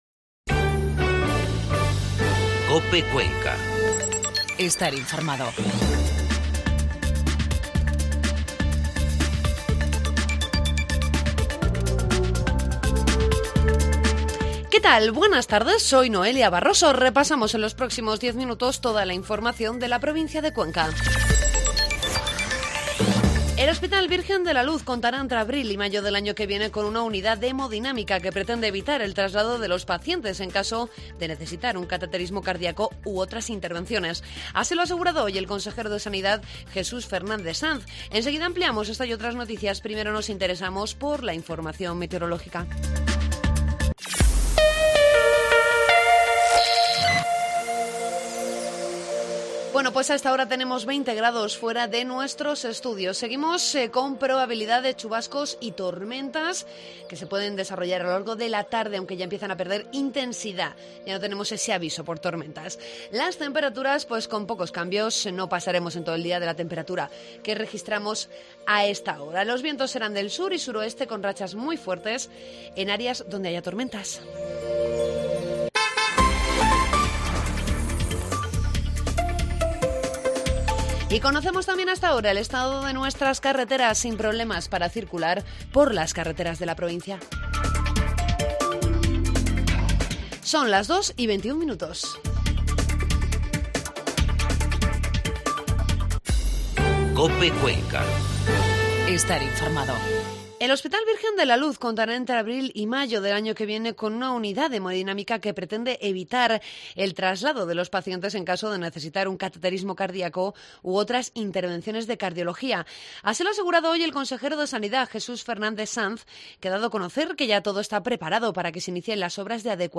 AUDIO: Informativo COPE Cuenca